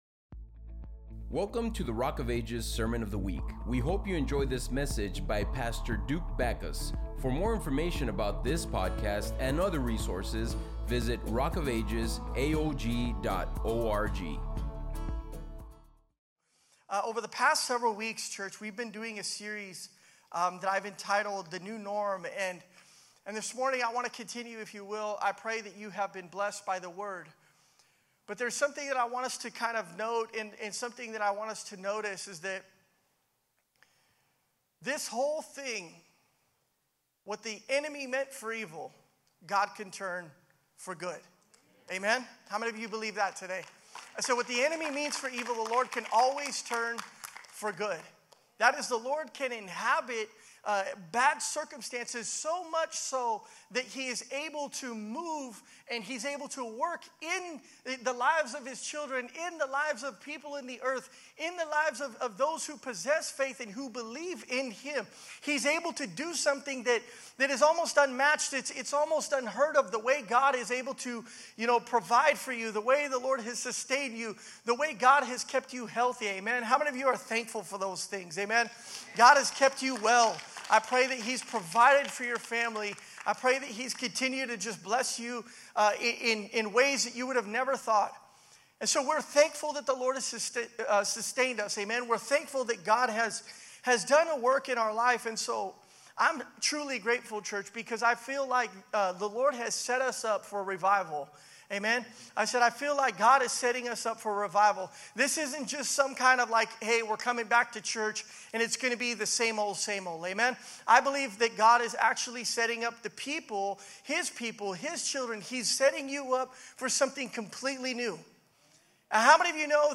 Sermon-Podcast.mp3